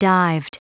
Prononciation du mot dived en anglais (fichier audio)
Prononciation du mot : dived